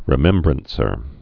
(rĭ-mĕmbrən-sər)